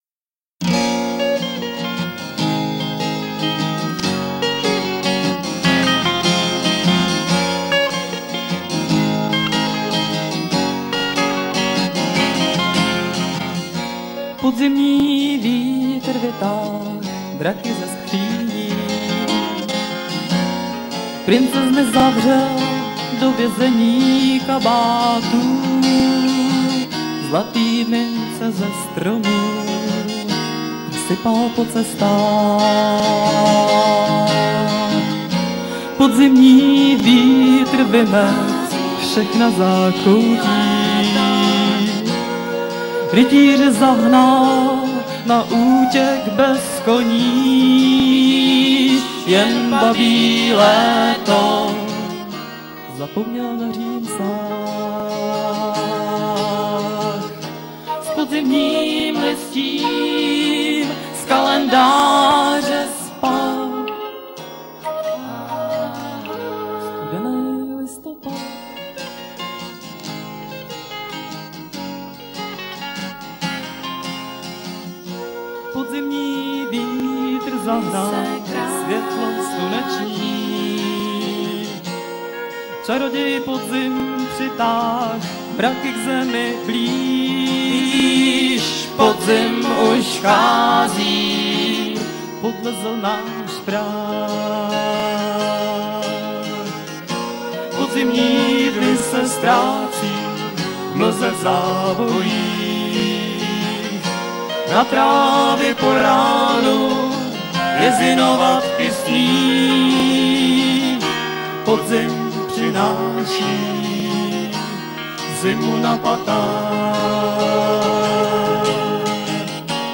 Gambit in memoriam LIVE